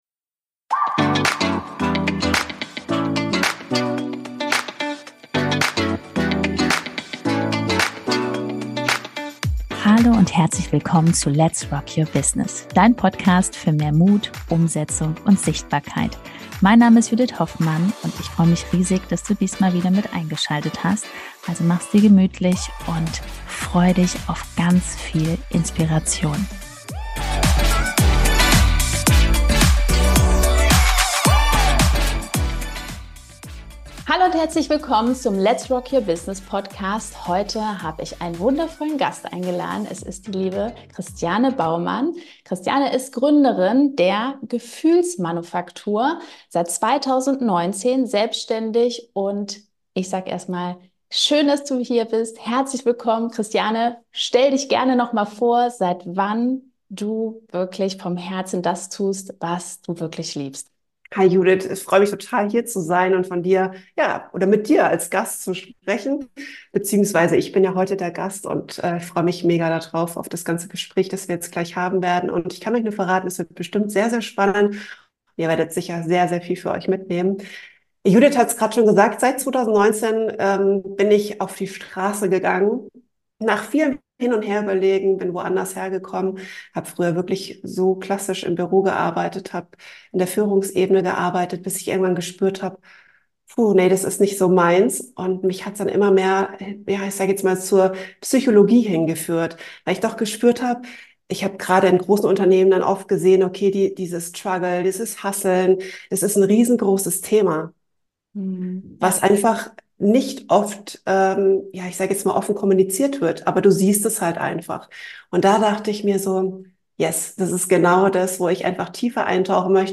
231 - Du funktionierst. Aber lebst Du auch? - Interview